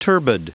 Prononciation du mot turbid en anglais (fichier audio)
Prononciation du mot : turbid